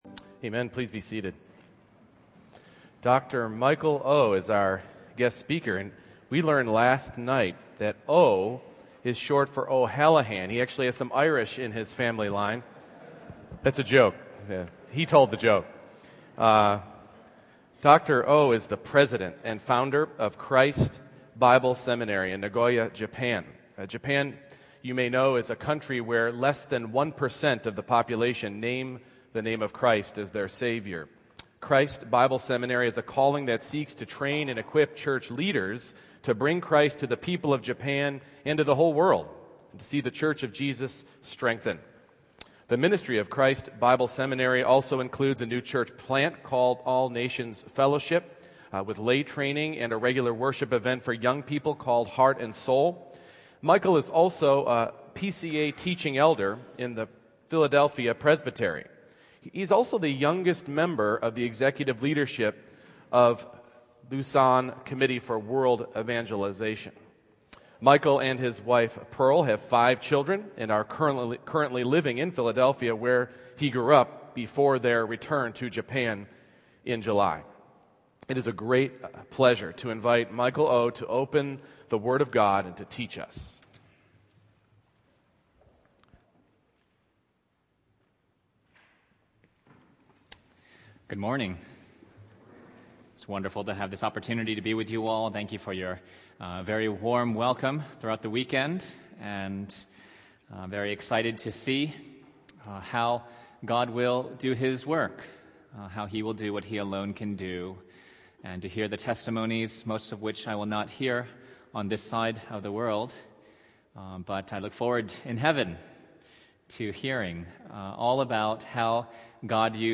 Passage: Psalm 22:1-31 Service Type: Morning Worship